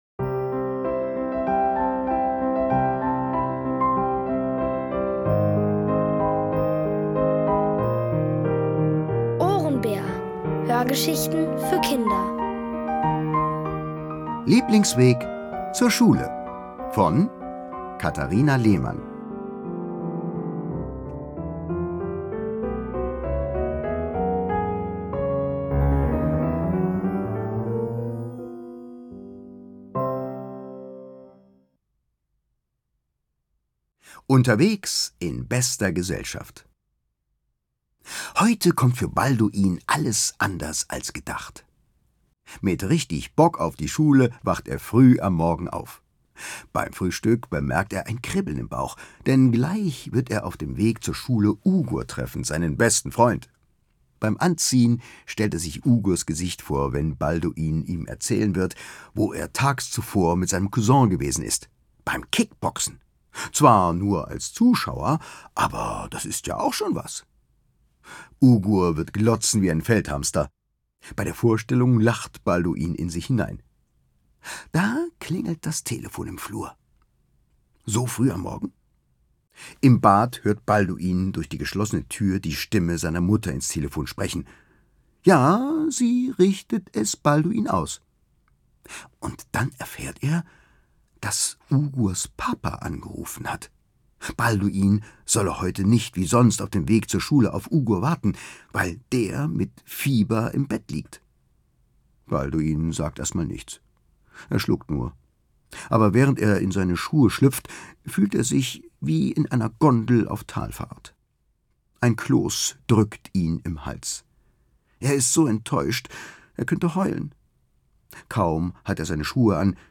Von Autoren extra für die Reihe geschrieben und von bekannten Schauspielern gelesen.
Es liest: Thomas Nicolai.